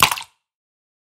Звук удаления файла